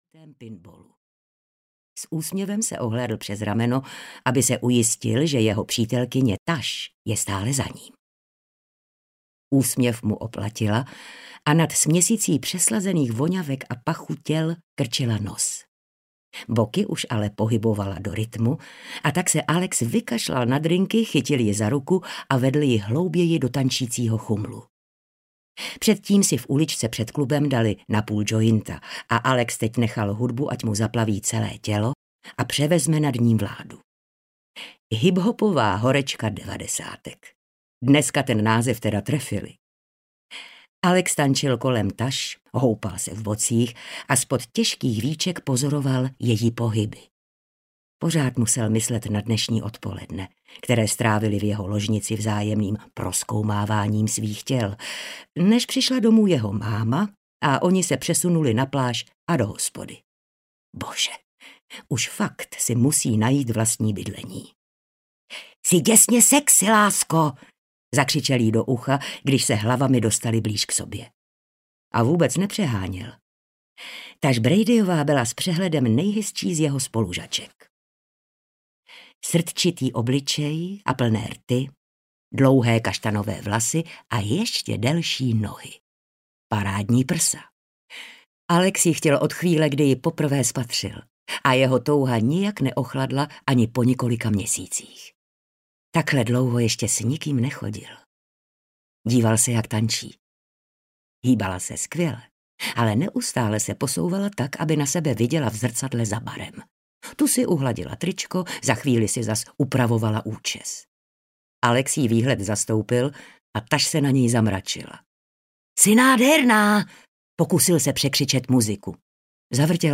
Inkoust audiokniha
Ukázka z knihy